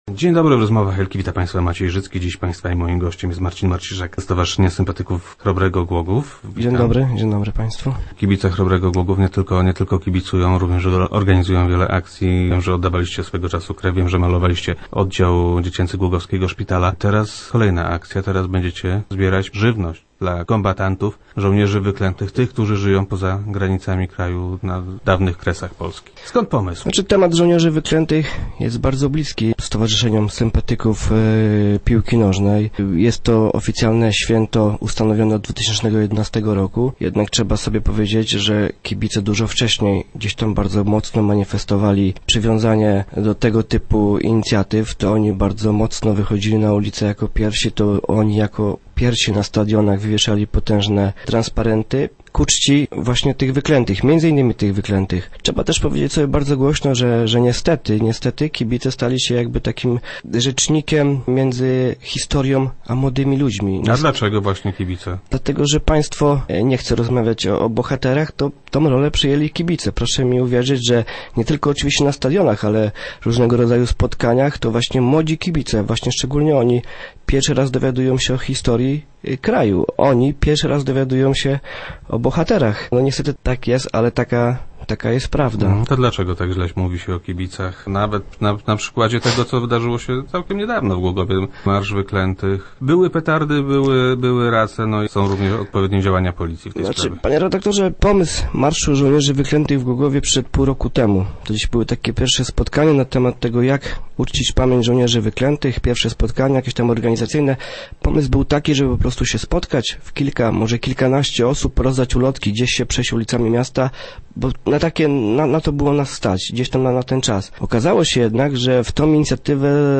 Rozmowy Elki